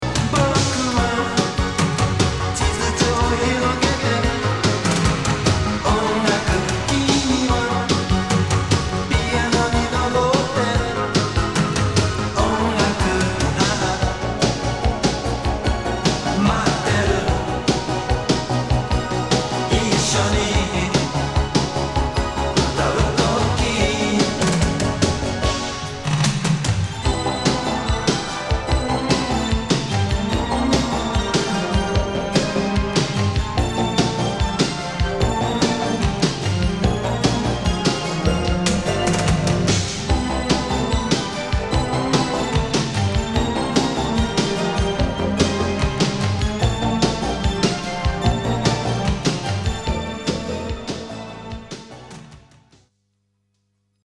帯付!!散開前ラスト･ライヴの模様を2LPで! 83年リリース。